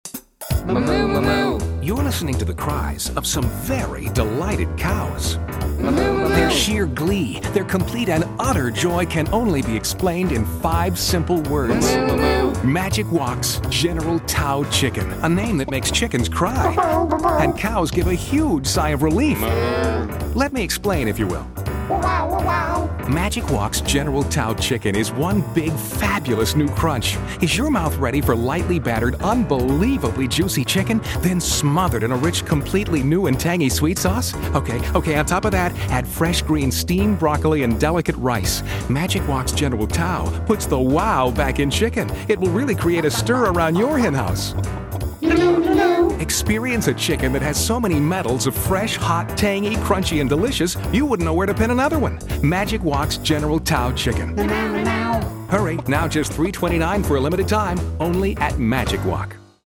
These radio commercials have all won Advertising Addy Awards.